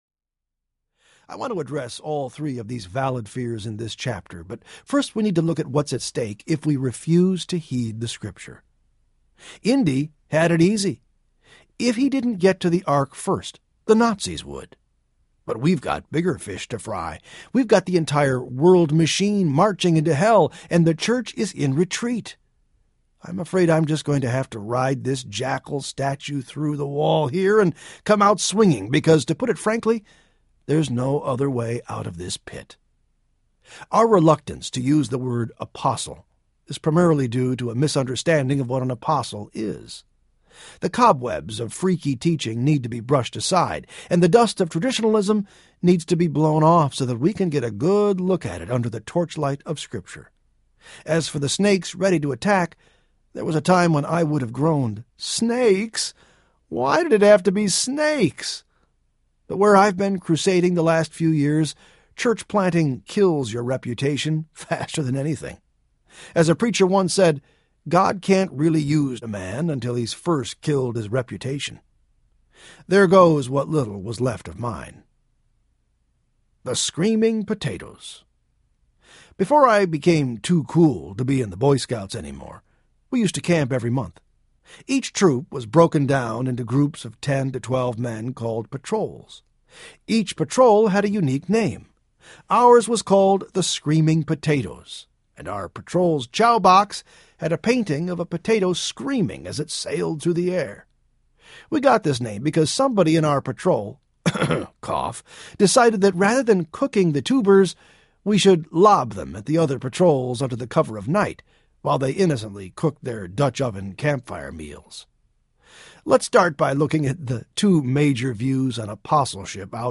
Church Zero Audiobook
Narrator
6.15 Hrs. – Unabridged